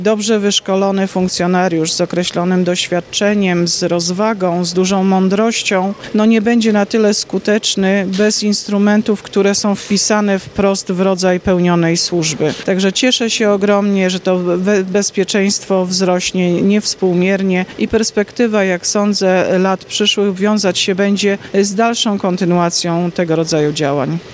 Dziś nastąpiło ich uroczyste przekazanie i poświęcenie.
– To jest kolejny, bez wątpienia ważny dzień – mówiła starosta stargardzki Iwona Wiśniewska.